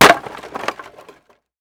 wood_plank_break1.wav